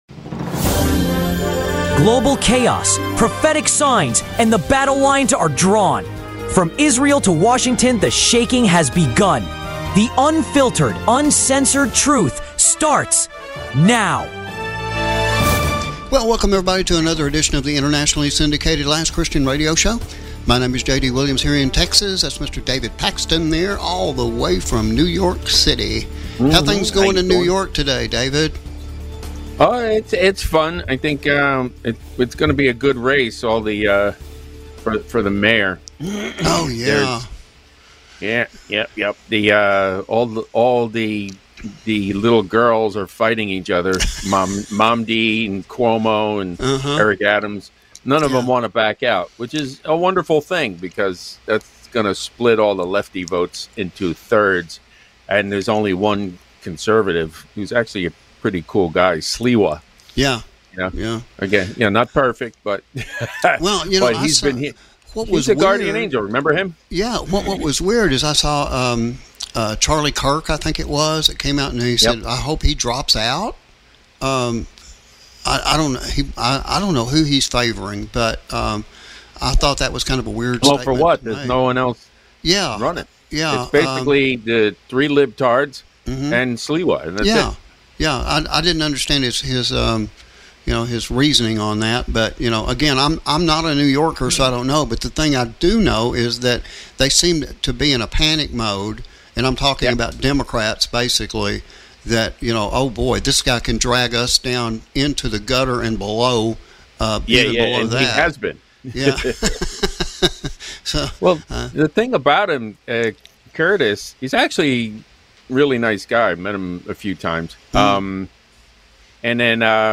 This week’s headlines include: * Escalating war in Gaza and Iran’s growing axis of evil* President Trump’s new tariffs and America’s economic awakening* Flash floods and supernatural climate signals from Texas to Europe* BRICS, global surveillance, and the tightening grip of world governance Prophecy is no longer on the horizon.